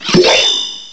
cry_not_doublade.aif